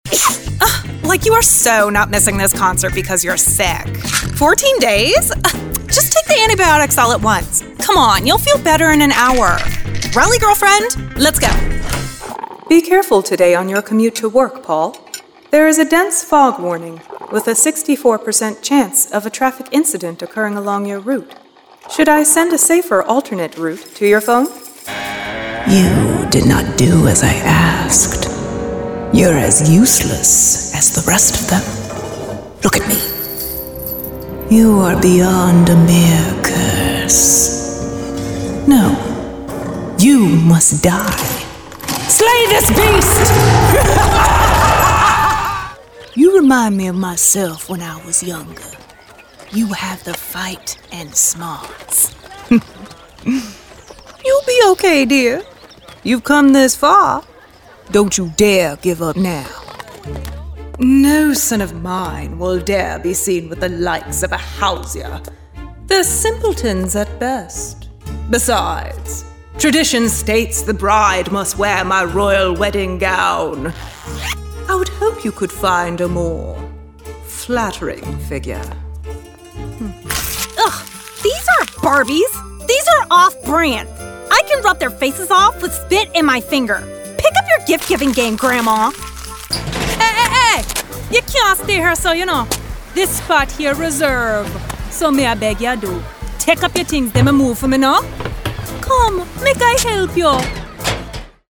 Young Adult, Adult
Location: Los Angeles, CA, USA Languages: english japanese spanish | latin american Accents: black us british rp | natural standard us | natural white south african | natural Voice Filters: VOICEOVER GENRE ANIMATION 🎬 COMMERCIAL 💸 NARRATION 😎